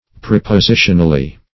-- Prep`o*si"tion*al*ly , adv.